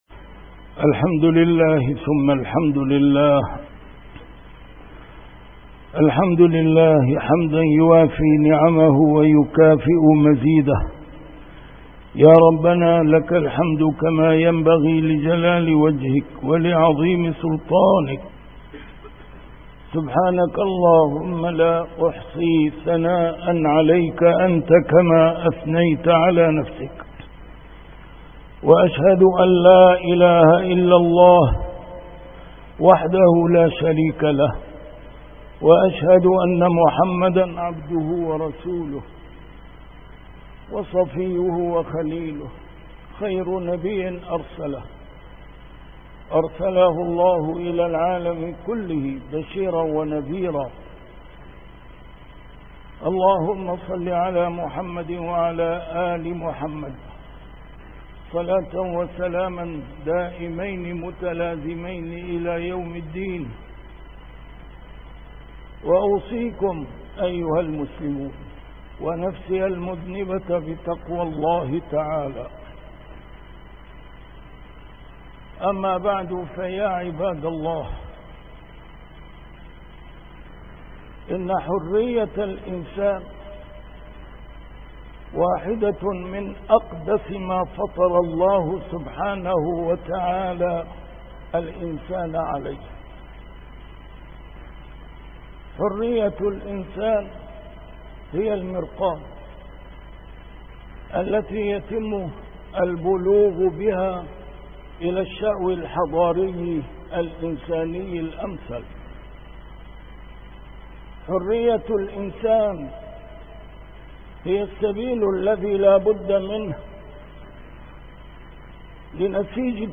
A MARTYR SCHOLAR: IMAM MUHAMMAD SAEED RAMADAN AL-BOUTI - الخطب - الطريق إلى الحرية